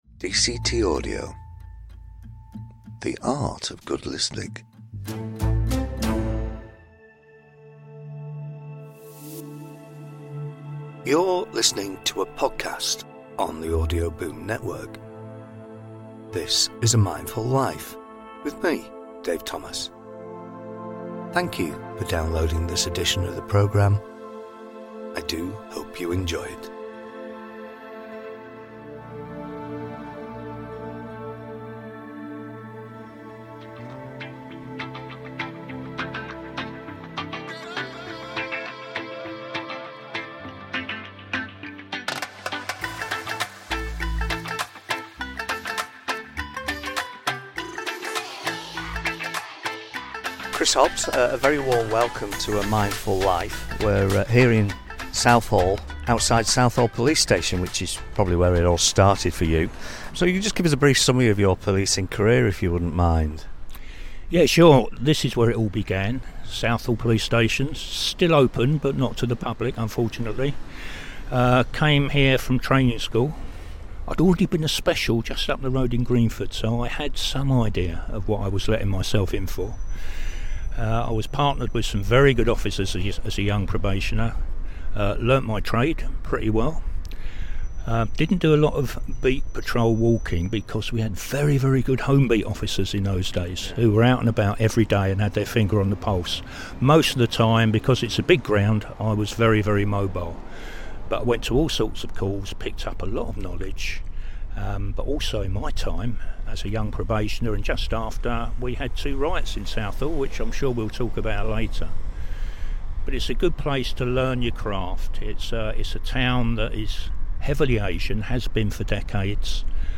This is the first of a series of five podcasts recorded in London.